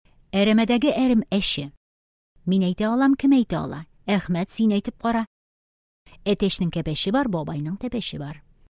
ФОНЕТИКА: ЗВУК [Ә]
Предлагаем вам потренироваться в правильном произношении звука [ә] с помощью следующих скороговорок:
Тизәйткечләр